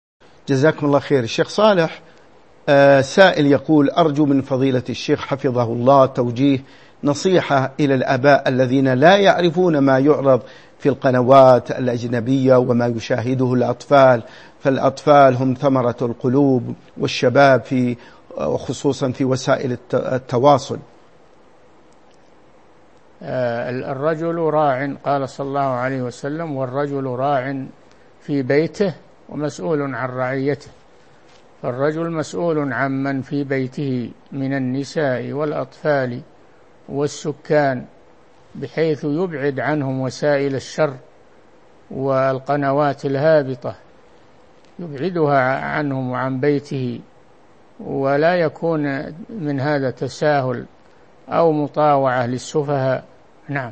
من مواعظ أهل العلم
Mono